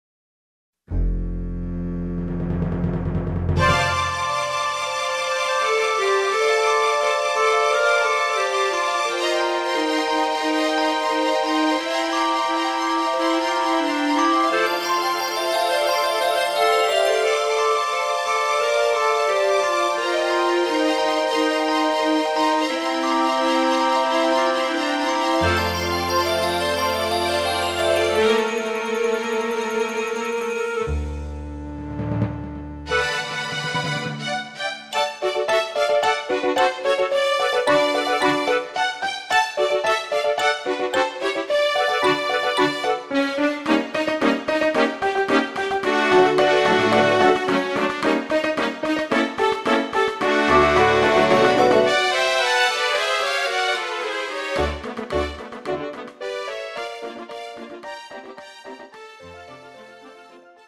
장르 뮤지컬 구분